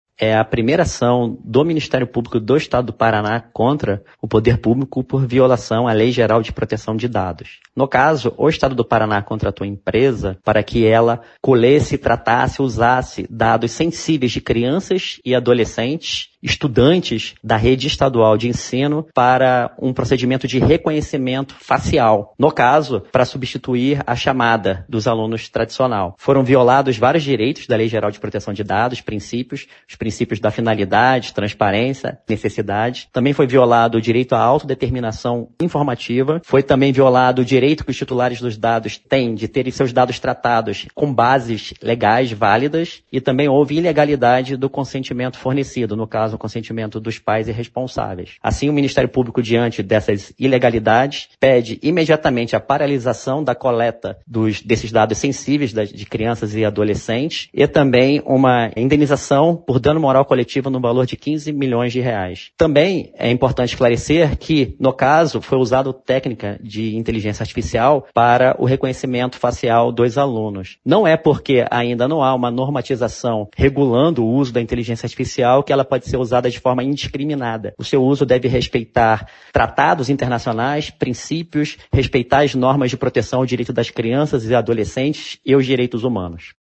Segundo o promotor de Justiça, Marcos José Porto Soares, vários direitos previstos na LGPD foram violados.